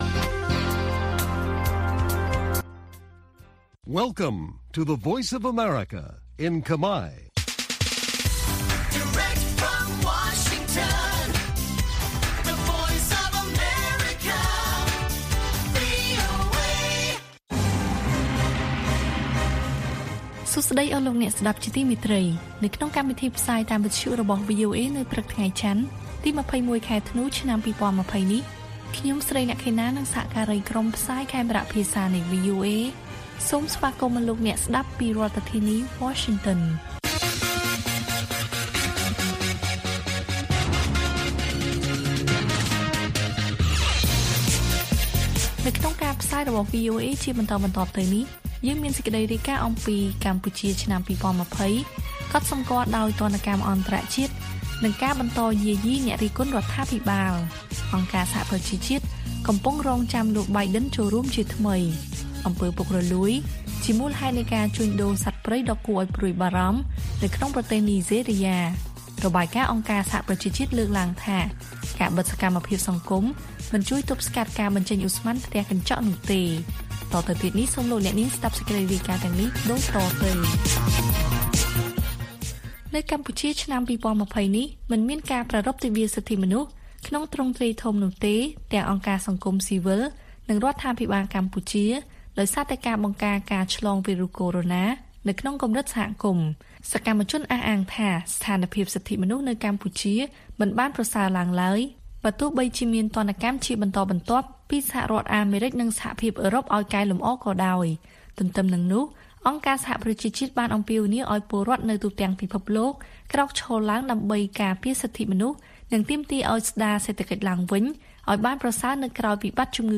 ព័ត៌មានពេលព្រឹក៖ ២១ ធ្នូ ២០២០